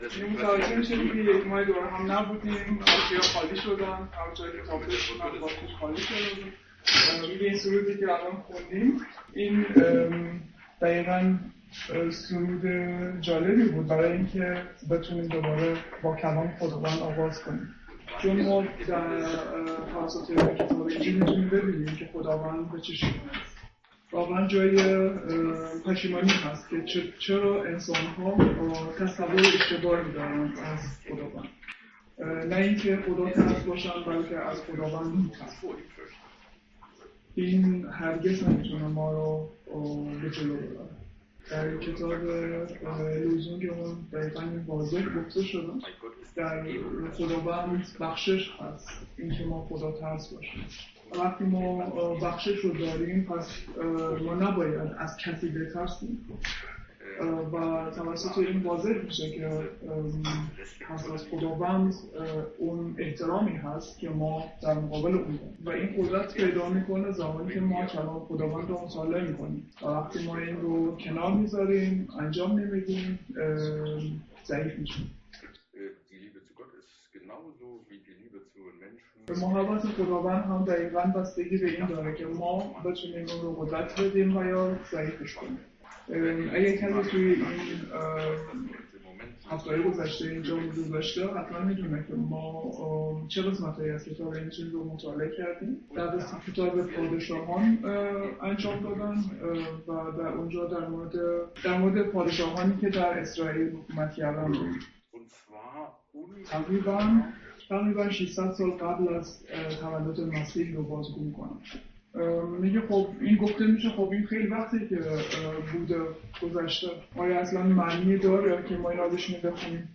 Könige 5,1-19 | Übersetzung in Farsi